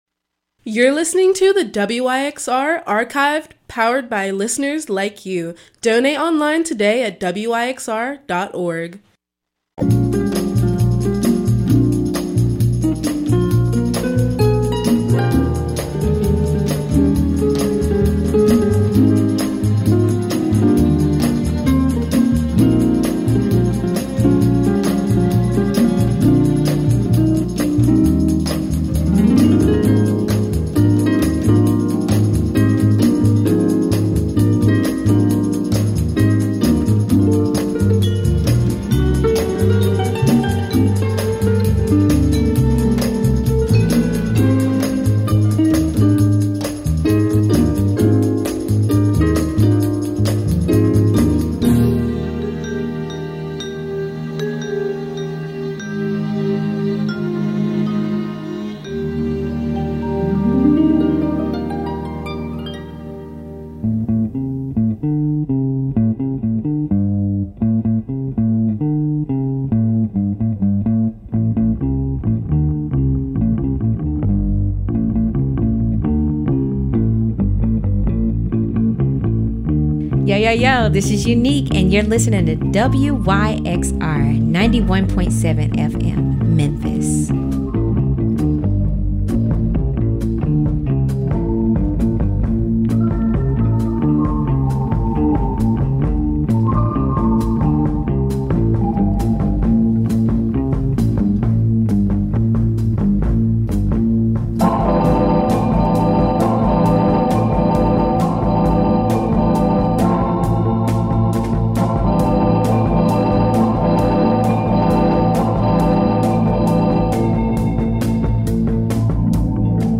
World Dance